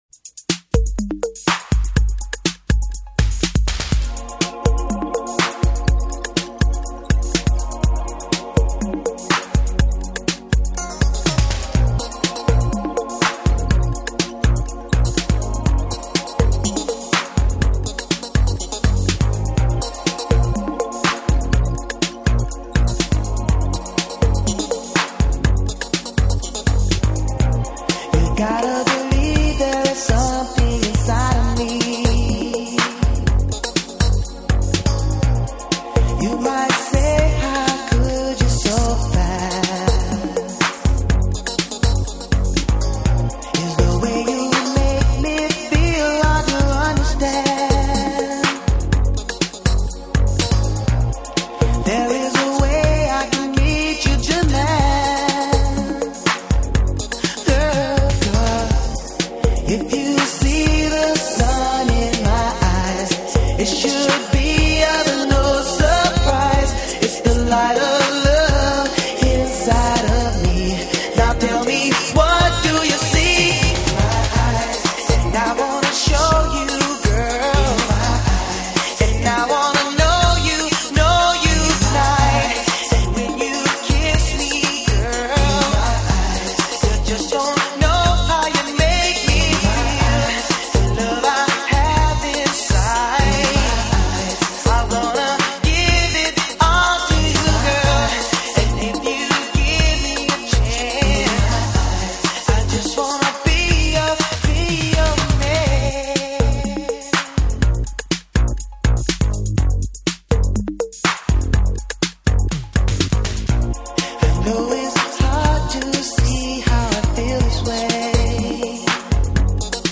light dance-pop sound